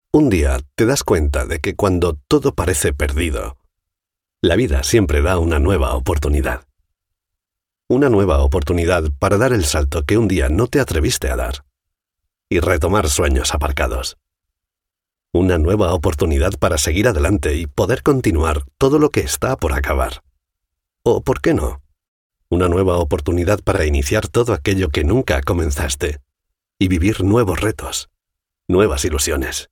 spanish castilian voice talent, spanish freelance voice over. locutor andaluz
kastilisch
Sprechprobe: Industrie (Muttersprache):